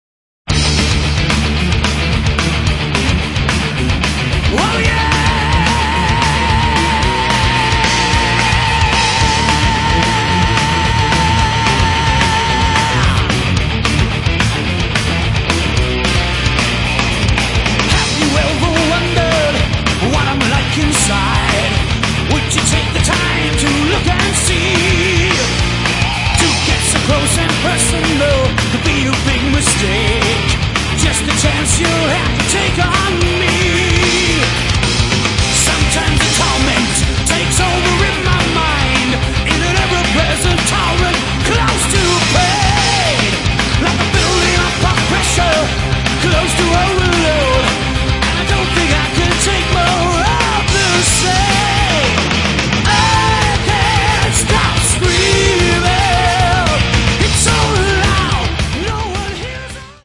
Metal
вокал
гитара
бас, бэк-вокал
барабаны, клавиши, бэк-вокал